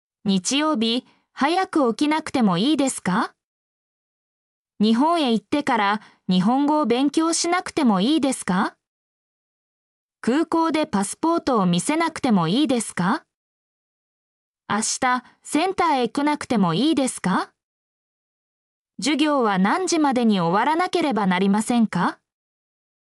mp3-output-ttsfreedotcom-42_gznCCZb6.mp3